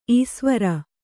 ♪ īsvara